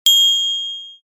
Short Clear Bell Ding Sound Effect
Use this short clear bell ding sound effect with a tink tone to add a crisp, bright accent to your project.
Enhance videos, games, and creative media with a clean, attention-grabbing bell sound.
Short-clear-bell-ding-sound-effect.mp3